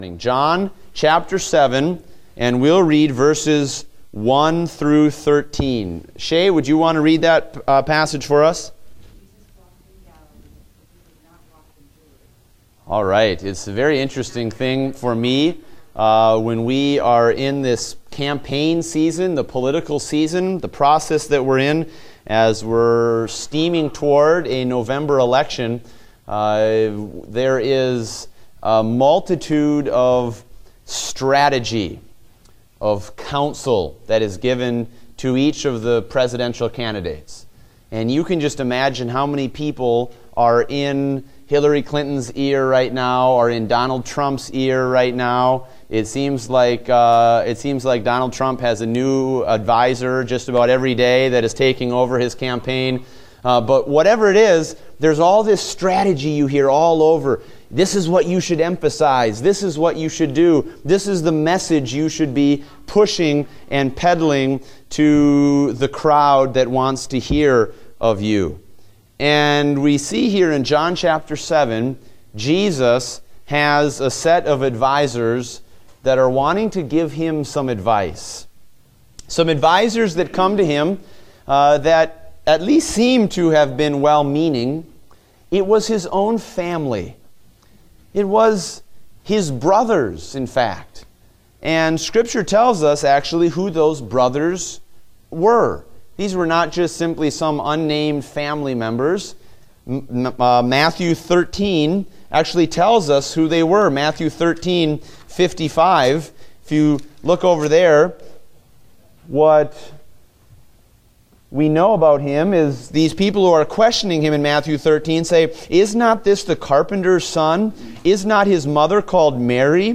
Date: August 28, 2016 (Adult Sunday School)